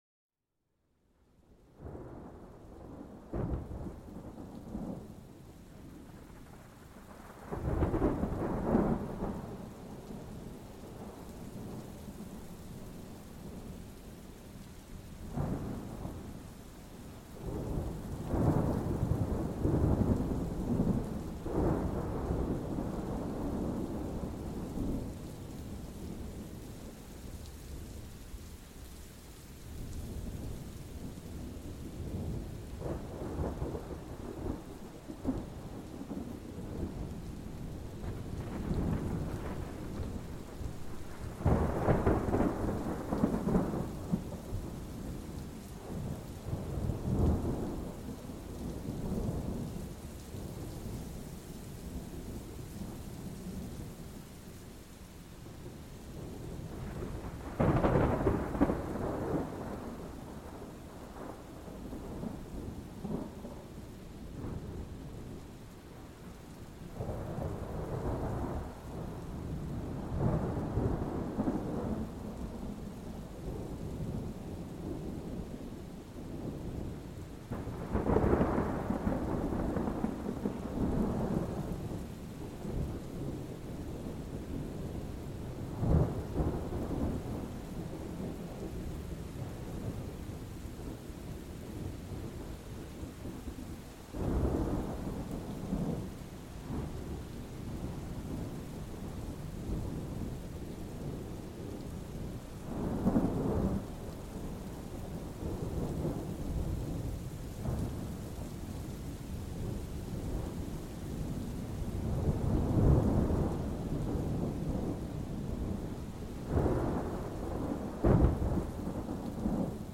Plongez dans le tumulte d'un orage puissant et laissez-vous envahir par le grondement intense du tonnerre et la pluie torrentielle. Cet épisode vous transporte au cœur d'une tempête, parfait pour une expérience auditive immersive et stimulante.